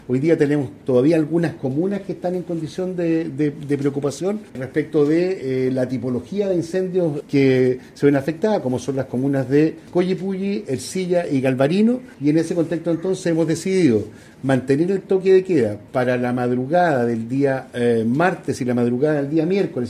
En la última reunión del Comité de Gestión de Riesgos y Desastres (Cogrid), tras el respectivo informe técnico se resolvió mantener el toque de queda en tres comunas de La Araucanía, tal como lo detalló el delegado Presidencial, Eduardo Abdala